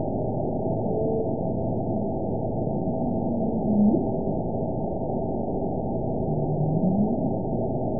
event 918664 date 12/07/23 time 18:00:47 GMT (1 year, 5 months ago) score 9.27 location TSS-AB02 detected by nrw target species NRW annotations +NRW Spectrogram: Frequency (kHz) vs. Time (s) audio not available .wav